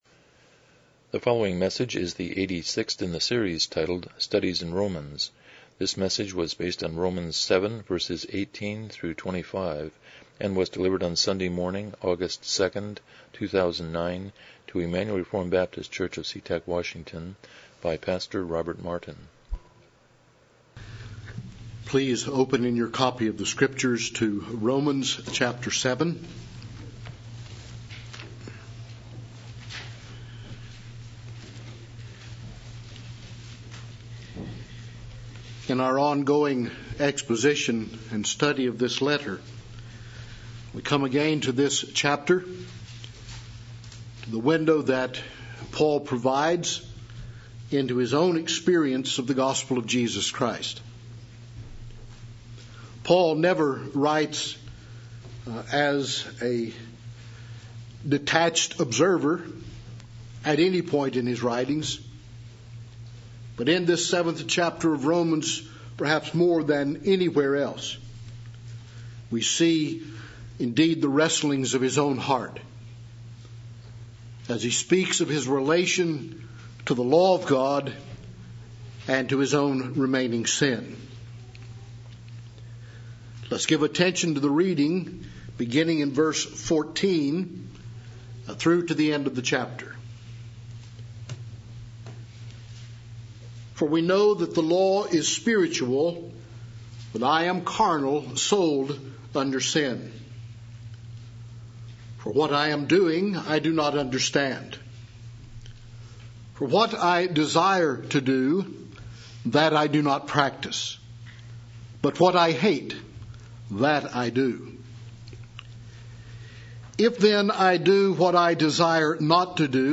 Romans 7:18-25 Service Type: Morning Worship « 41 The Abrahamic Covenant